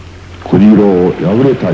と次々に歴史上の人物達の語りかけです。